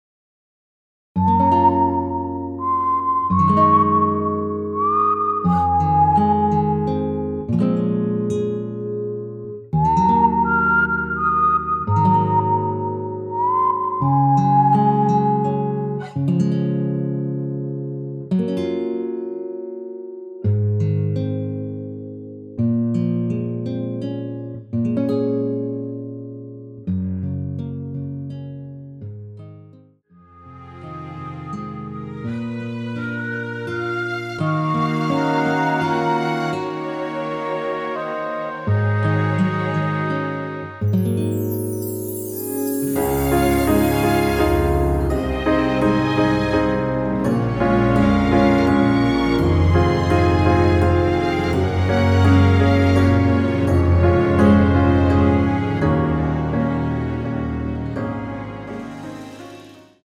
무반주 구간 들어가는 부분과 박자 맞출수 있게 쉐이커로 박자 넣어 놓았습니다.(일반 MR 미리듣기 참조)
Db
앞부분30초, 뒷부분30초씩 편집해서 올려 드리고 있습니다.
중간에 음이 끈어지고 다시 나오는 이유는